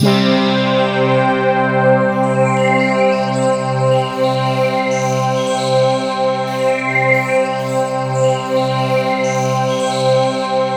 BRASSPADC3-R.wav